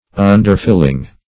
Search Result for " underfilling" : The Collaborative International Dictionary of English v.0.48: Underfilling \Un"der*fill`ing\, n. The filling below or beneath; the under part of a building.